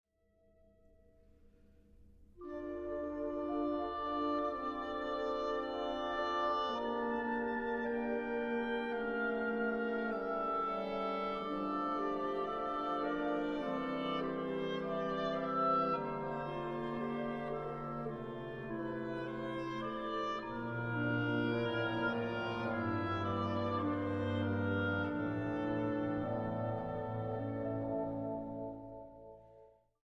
The Johnson State College Concert Band
Here are recordings of some of the pieces we are playing this semester: